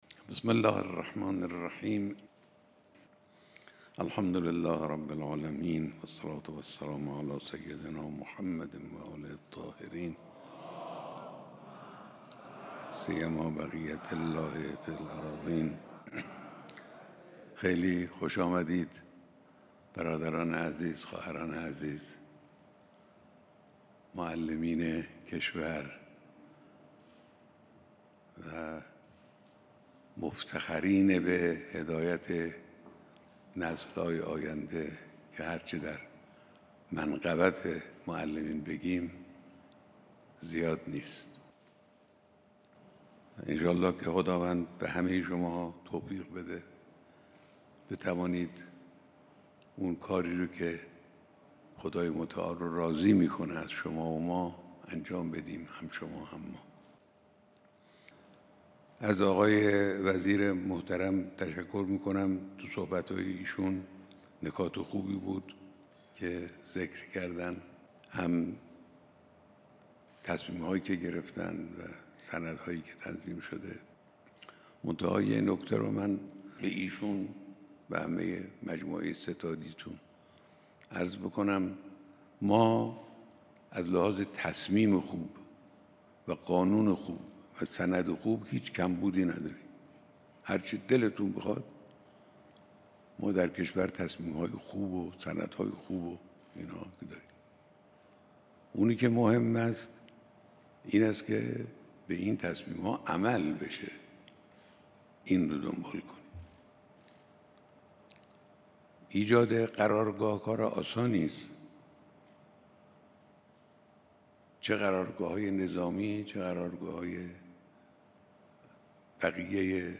بیانات در دیدار جمعی از معلمان